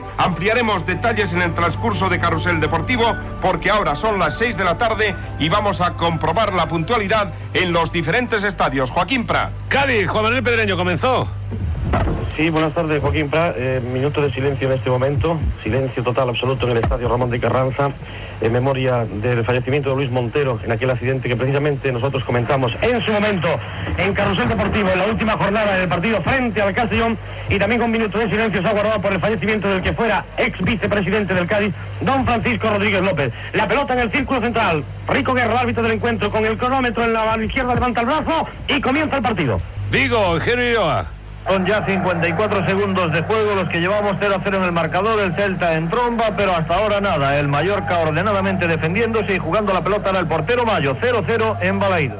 Ronda de partits de futbol amb connexions amb Cadis i Vigo
Esportiu